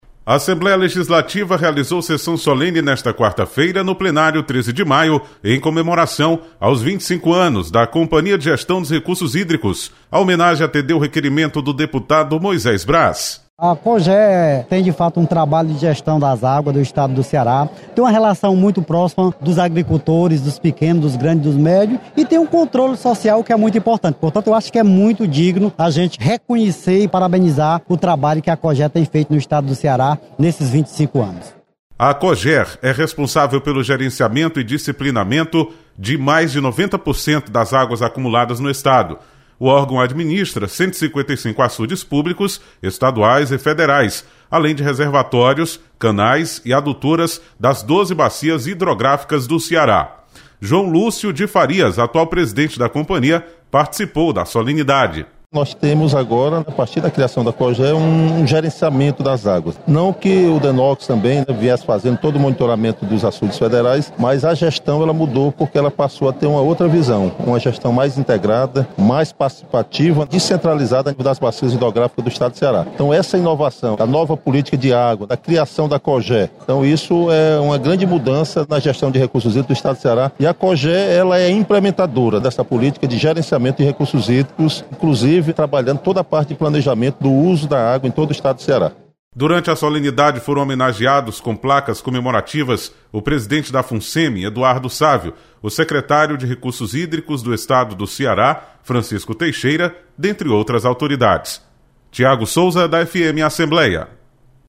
Sessão solene